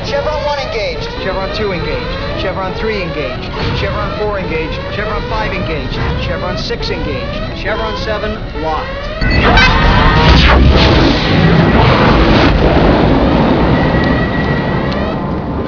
*Chevrons 1 to 7 being engaged*
chevrons_engaged.wav